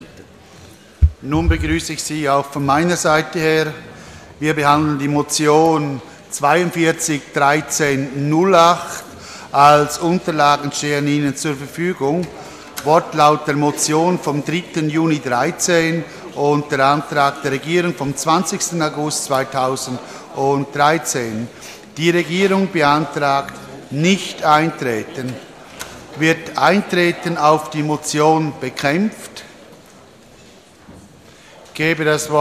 16.9.2013Wortmeldung
Ratsvizepräsident: Die Regierung beantragt Nichteintreten.
Session des Kantonsrates vom 16. bis 18. September 2013